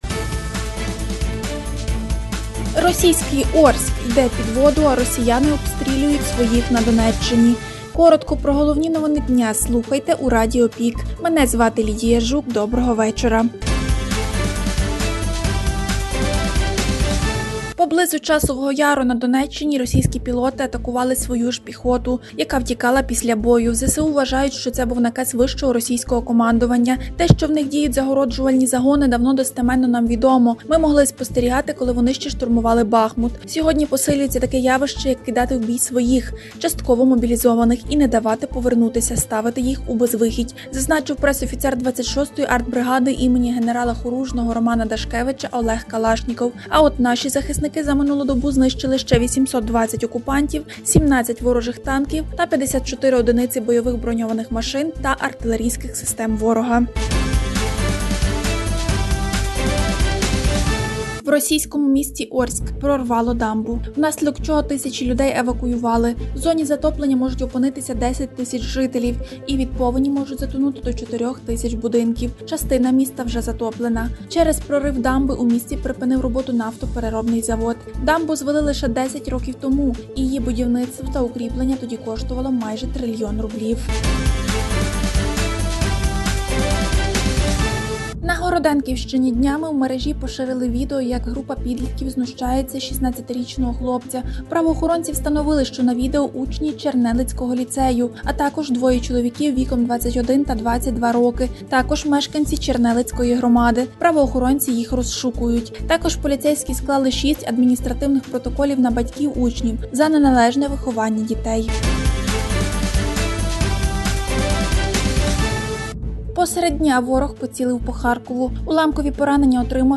Пропонуємо Вам актуальне за день у радіоформаті.